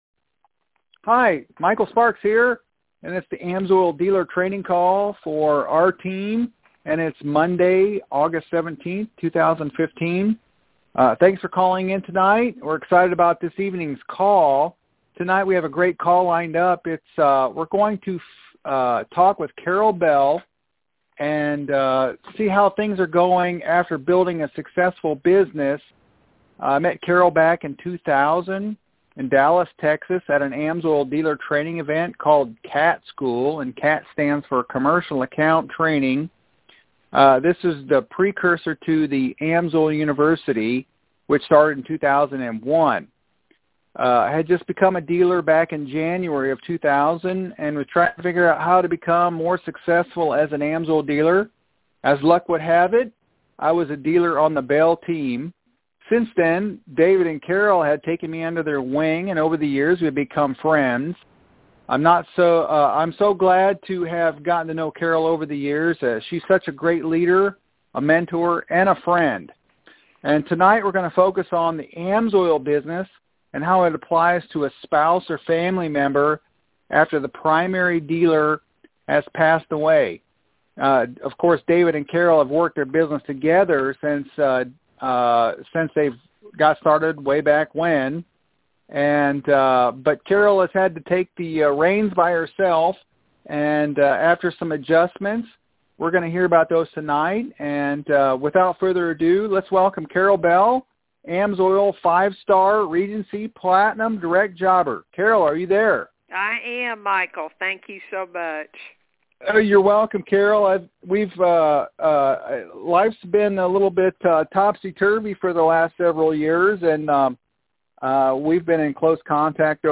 Sparks Team AMSOIL Dealer Training Call |August 17th, 2015